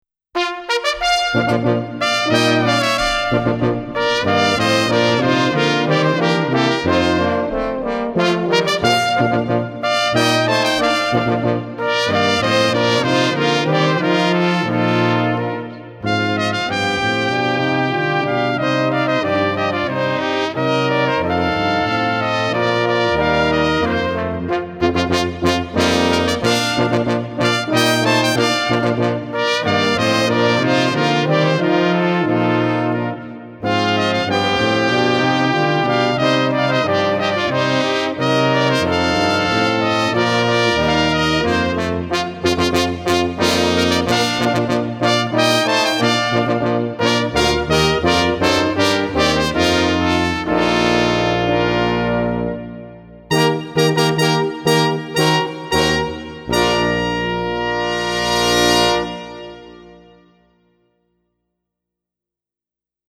Hejnał Gminy Zator
hejnal.mp3